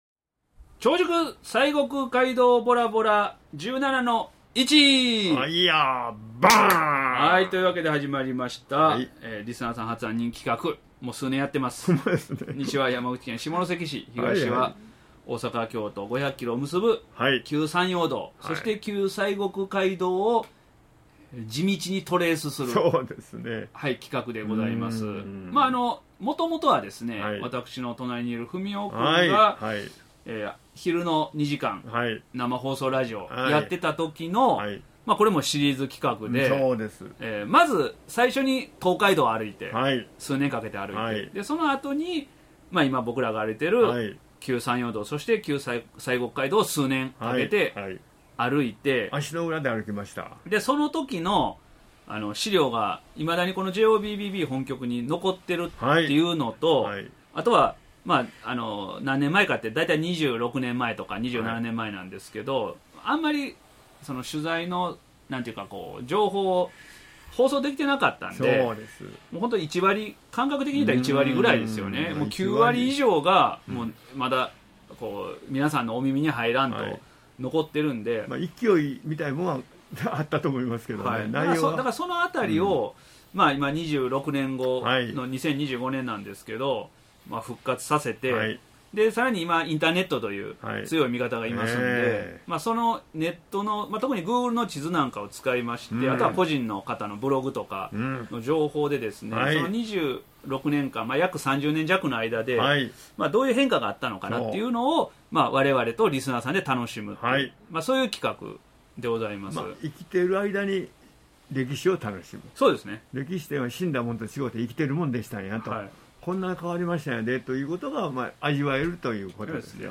２０２５年９月２５日（木）家族ラジオJOBBB８１１ 死の酷暑が終わりようやく過ごしやすい気候になった大阪北部の千里中央JOBBB本局 久しぶりに扇風機もクーラーも無い仮設スタジオから配信する家族ラジオ 秋の夜長はぜひJOBBBを聞きながらお過ごしください！